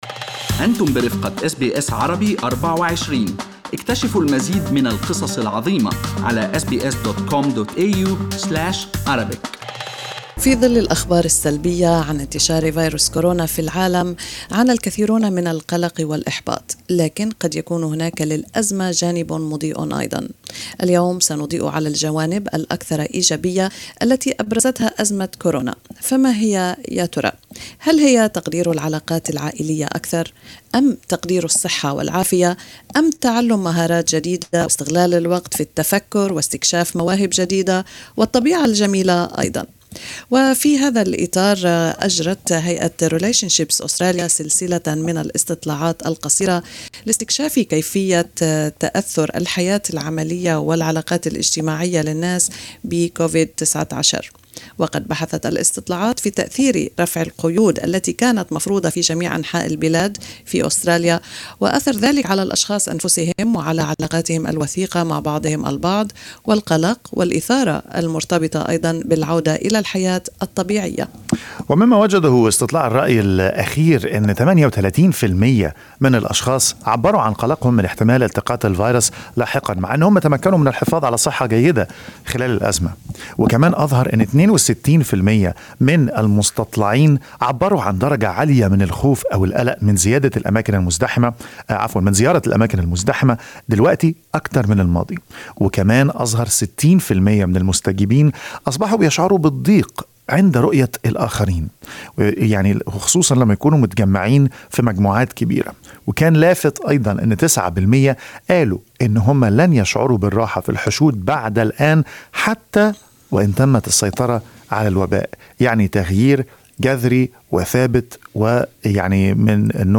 في حديث مع SBS Arabic24